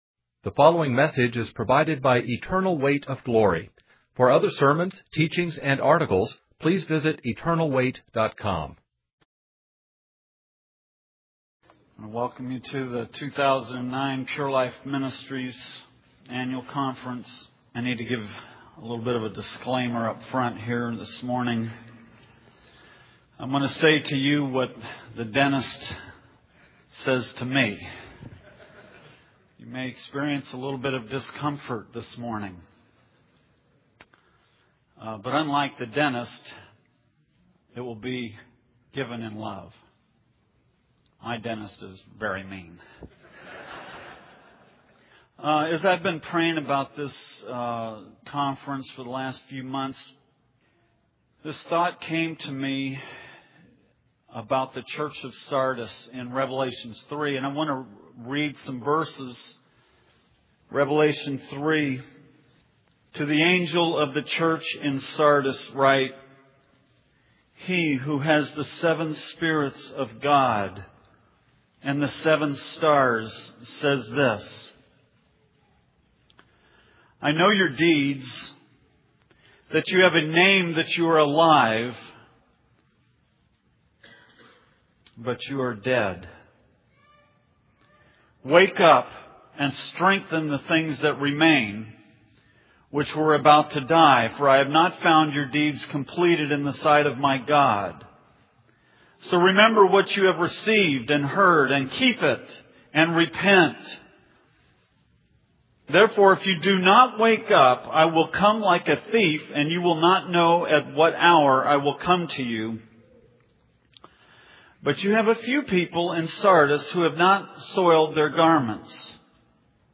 In this sermon, the speaker emphasizes the importance of making a commitment to God in light of the current events happening in the world.